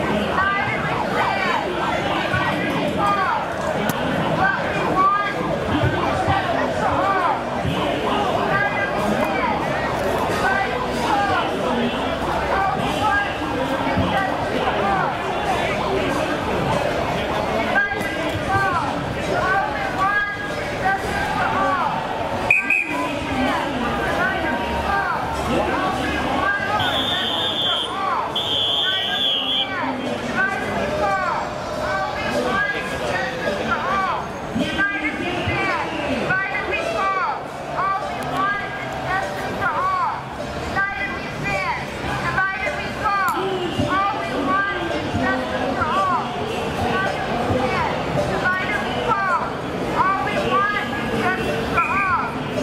Seattle, August 18, 2018, Liberty or Death Rally Counter Protester With Amplified Bullhorn
Often at political rallies, protesters repeatedly chanting political slogans through amplified bullhorns add to the cacophonous sonic blitz that typifies such events.